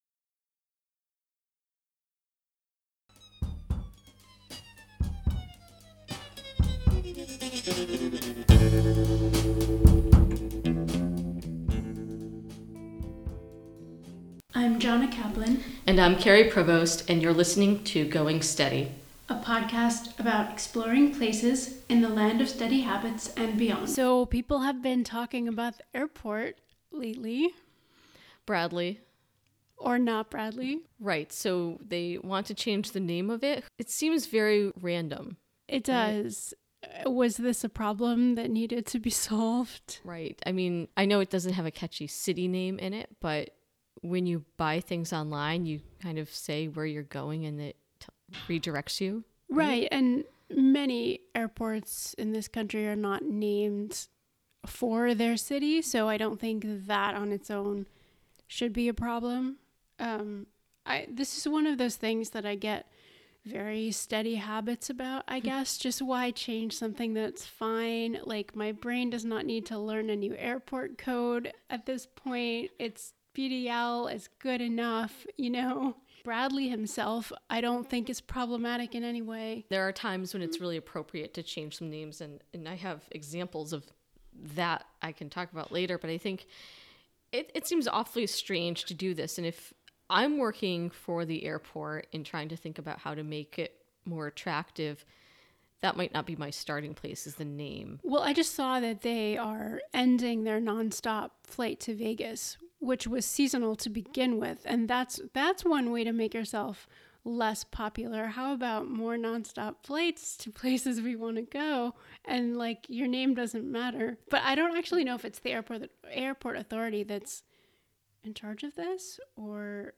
Note: This podcast may contain occasional, relatively lightweight curse-words.